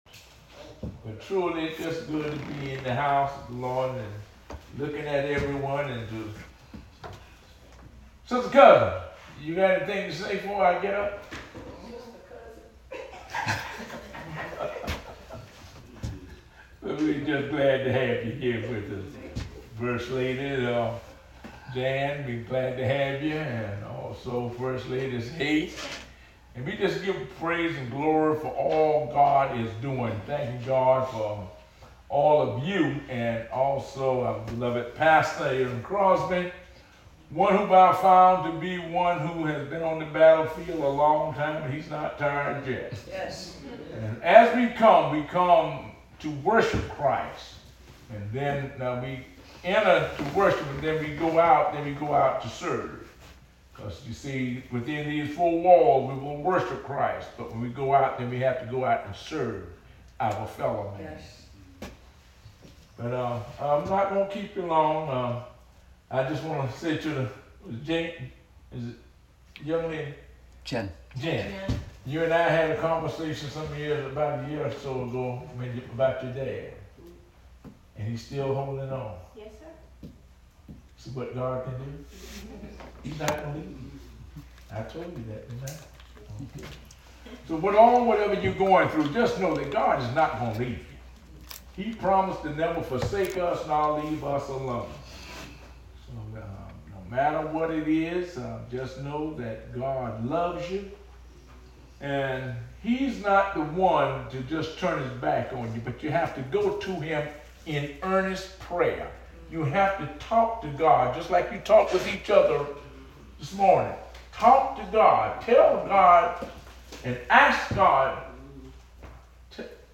September 29 2024 Sermon